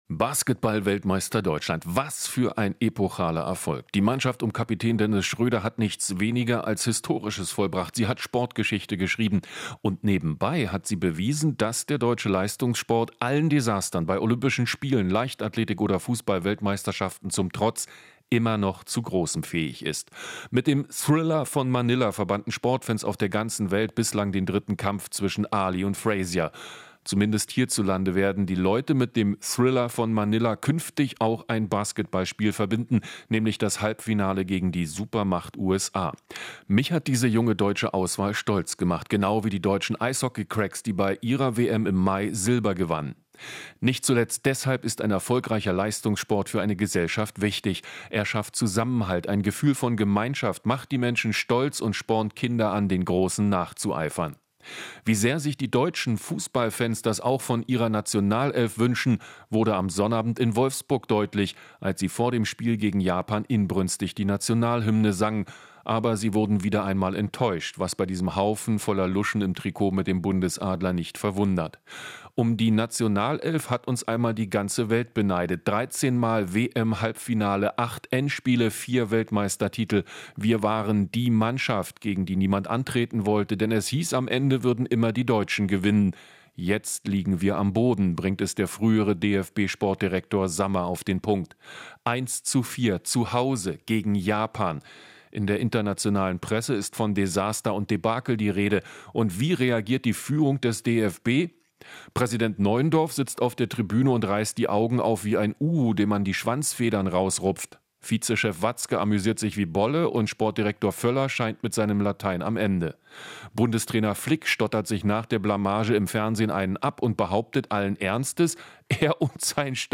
Inforadio Nachrichten, 11.09.2023, 14:20 Uhr - 11.09.2023